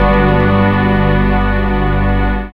5406L SYNSTR.wav